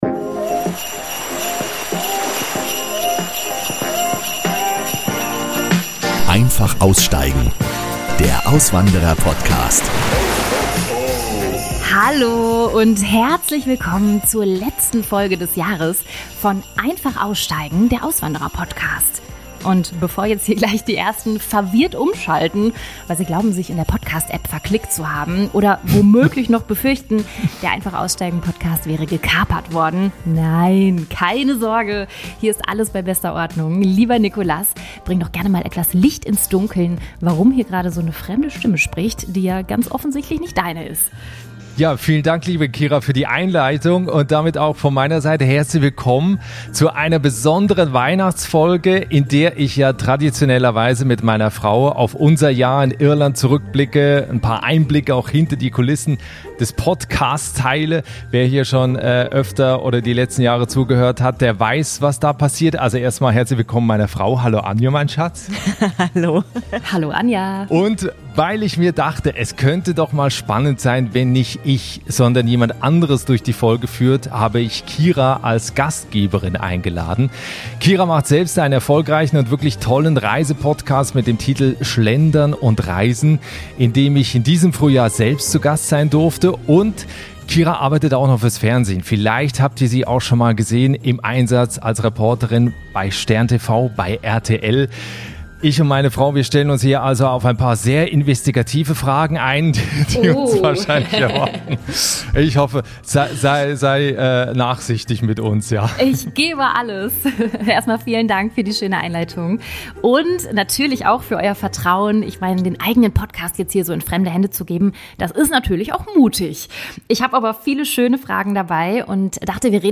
Eine befreundete Podcasterin übernimmt das Mikro und bringt uns dazu, mehr zu erzählen als sonst. Wie fühlt sich Weihnachten in einem fremden Land wirklich an?